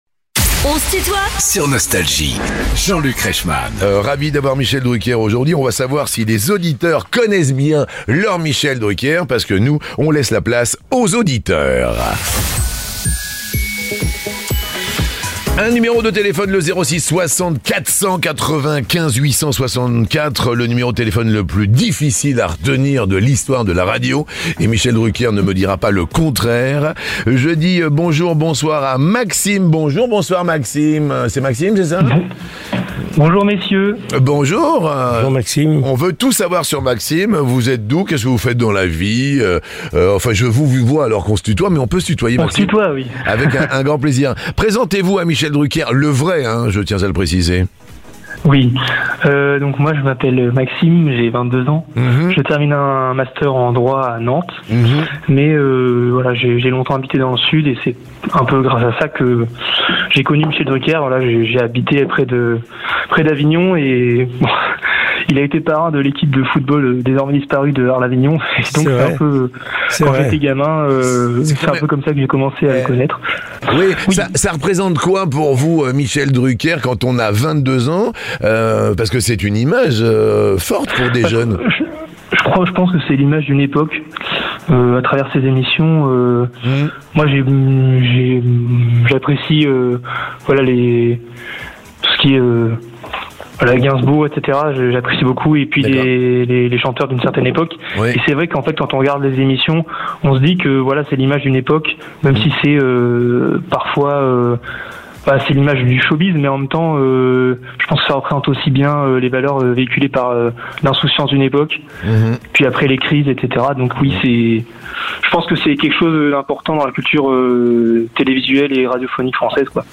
Tu connais bien ton Michel Drucker ? ~ Les interviews Podcast
Michel Drucker est l'invité de "On se tutoie ?..." avec Jean-Luc Reichmann